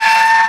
metal_m3.wav